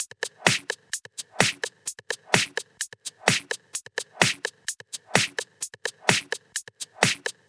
VFH1 128BPM Moonpatrol Kit 6.wav